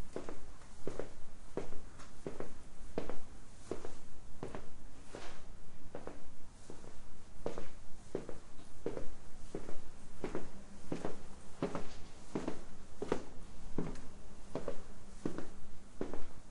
Shagi dom.ogg